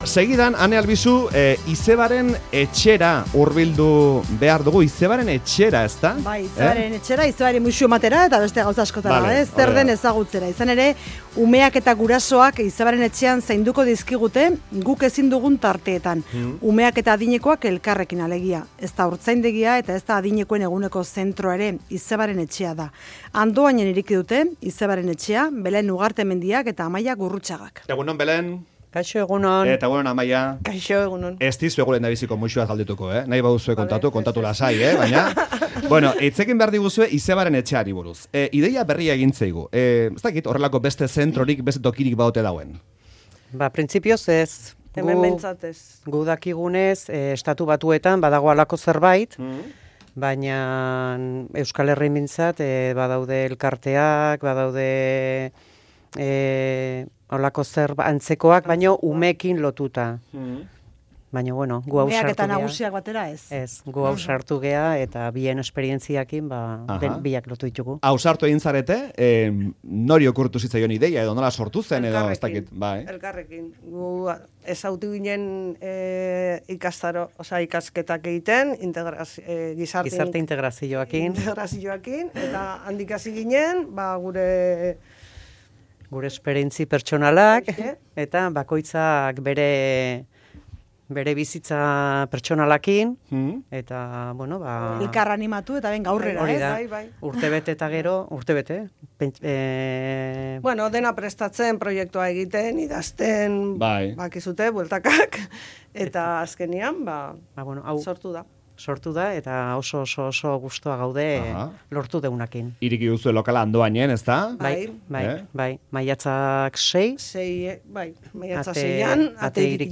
Edadetuak eta haurrak elkarrekin zainduko dituzte Andoaingo "Izebaren etxean". Arduradunekin hitz egin dugu Euskadi Irratiko Faktorian.